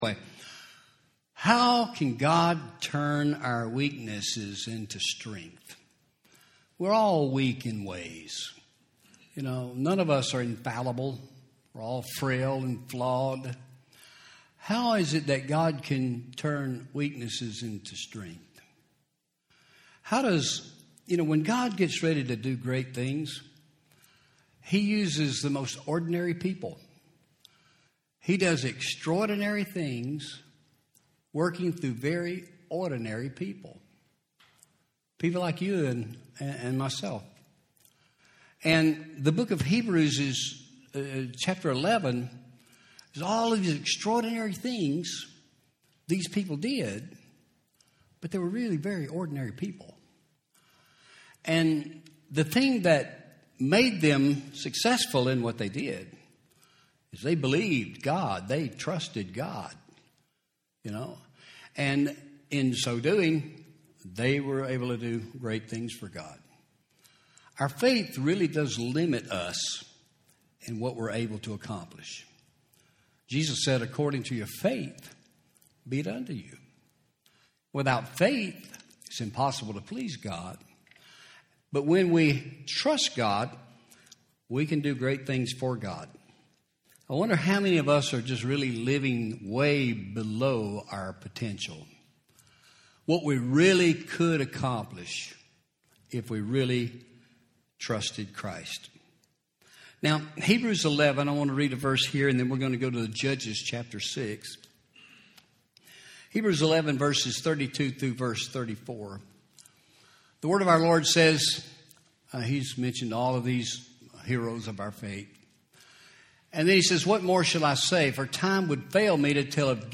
Home › Sermons › How God Turns Weakness Into Strength